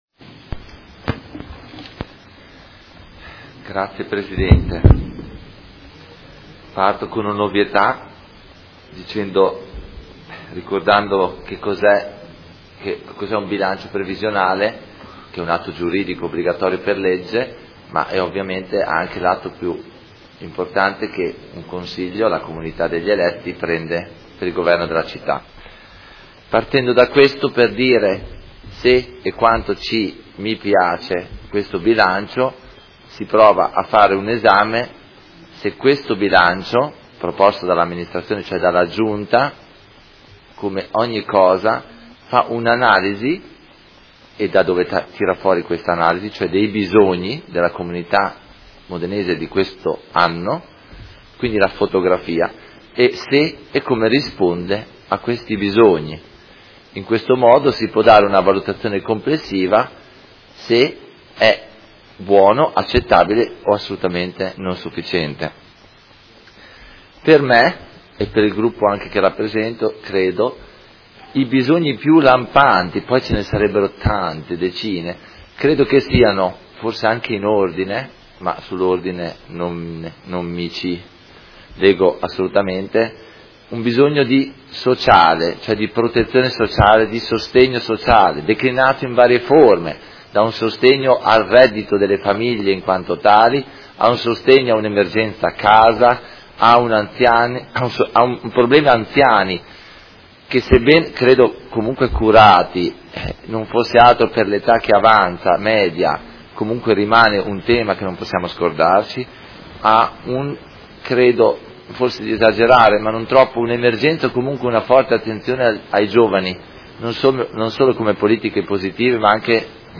Seduta del 26 gennaio. Bilancio preventivo: Dichiarazioni di voto